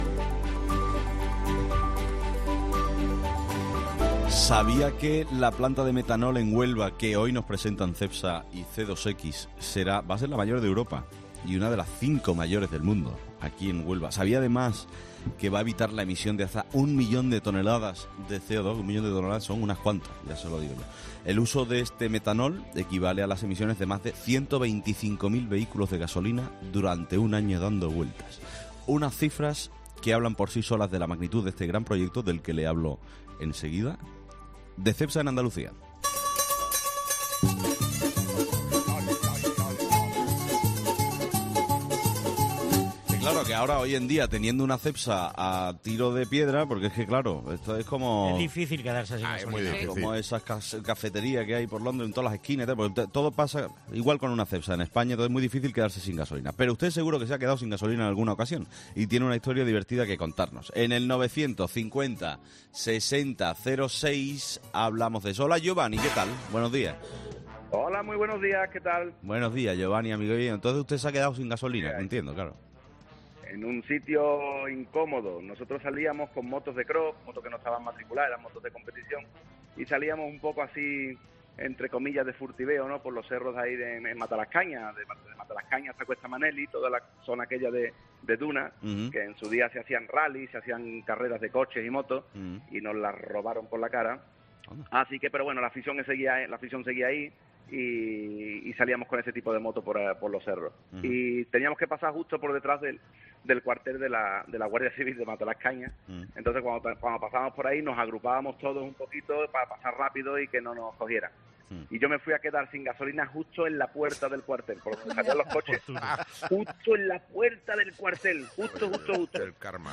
Otro oyente, al comienzo de su intervención, indicó que se quedó sin gasolina en un momento un tanto incómodo.